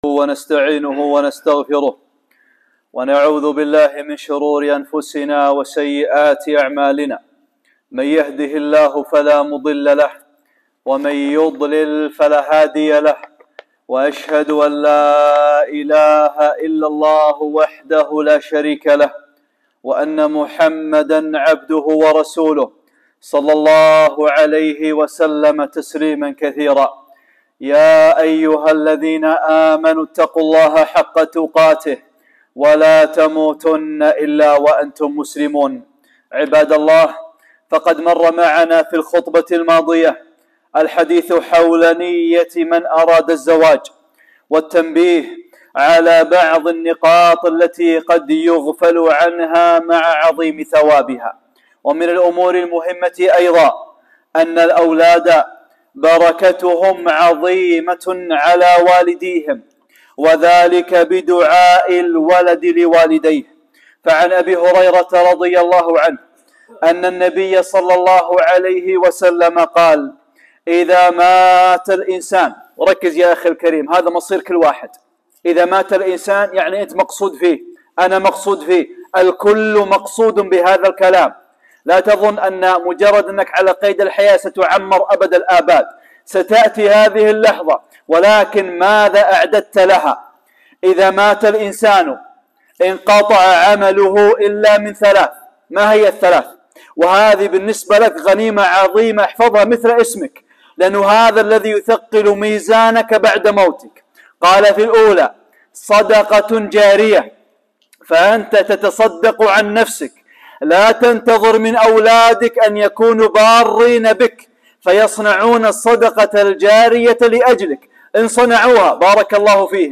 (6) خطبة - أولادكم نعمة، ولكن...!